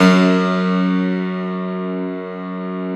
53a-pno04-F0.aif